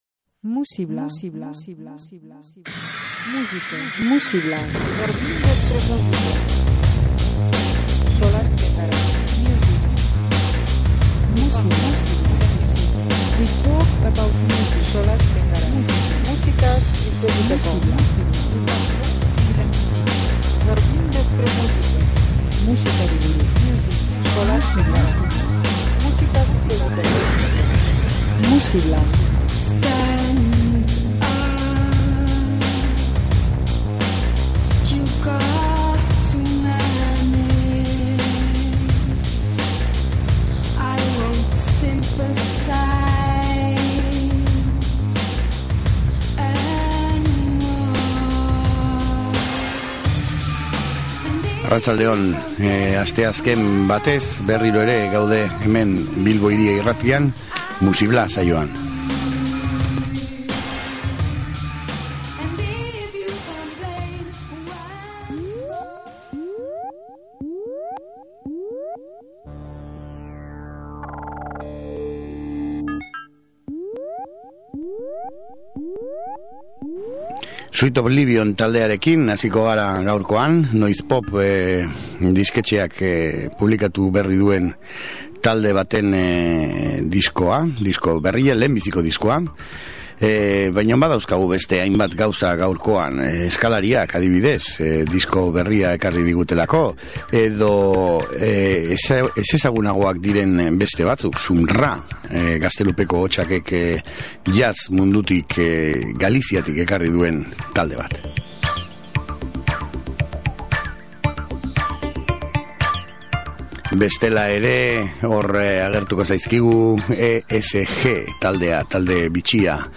bere funk minimalarekin